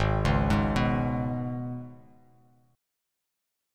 G#mbb5 chord